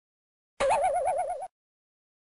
Confused Sound Effect Free Download